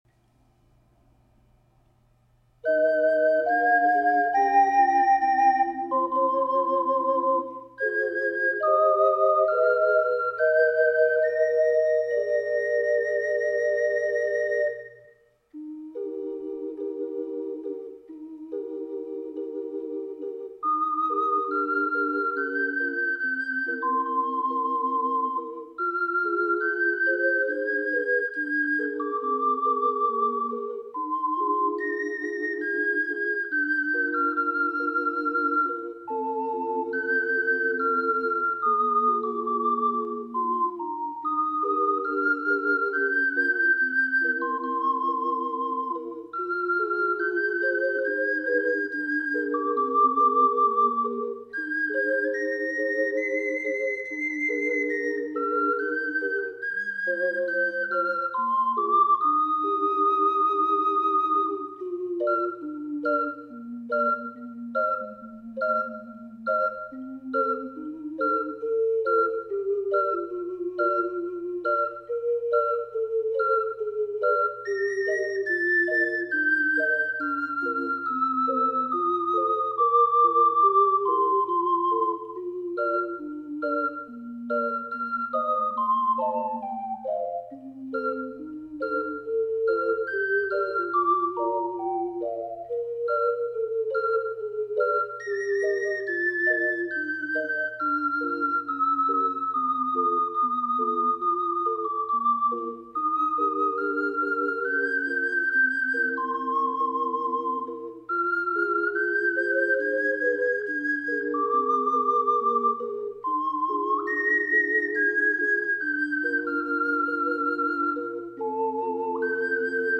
④BC   楽譜と同じ高さです。
◆2ndはオクターブ下を吹きました。